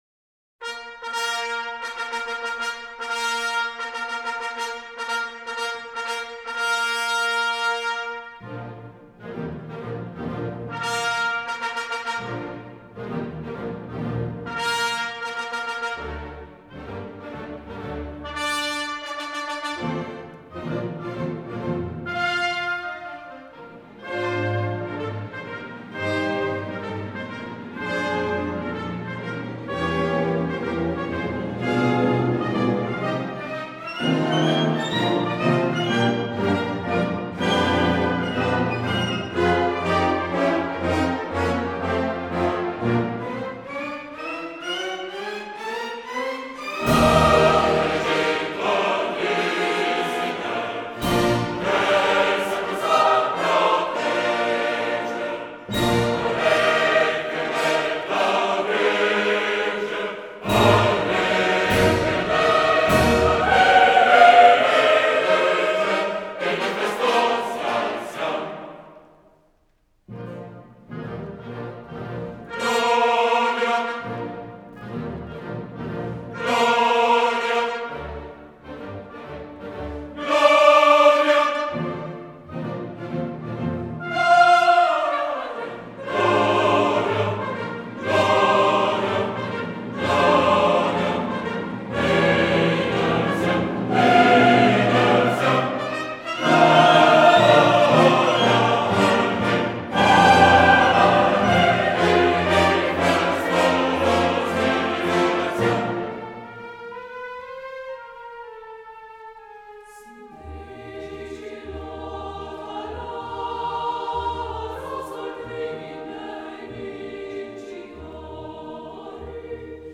Aida Radio Symphony Orchestra & Choir Ljubljana
Clásica